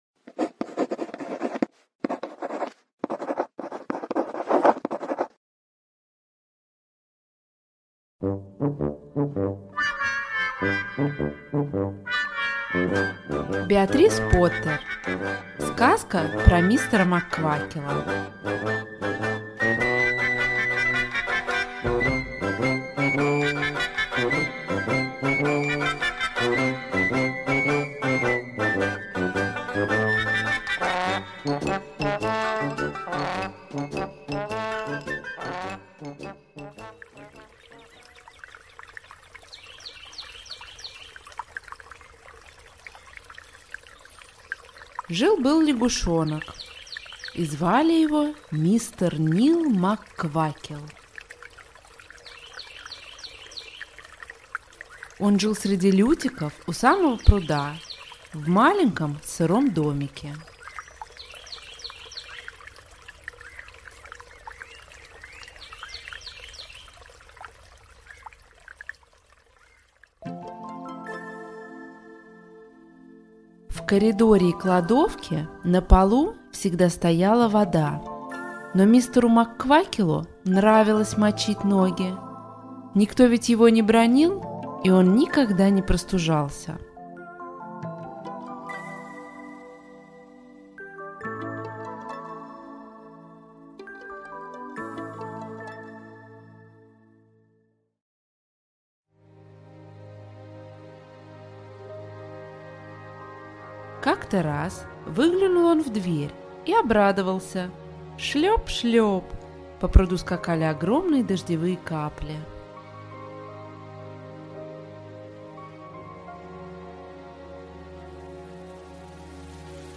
Сказка про мистера Мак-Квакила - аудиосказка Беатрис Поттер - слушать онлайн